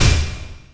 equip_metal_armor.wav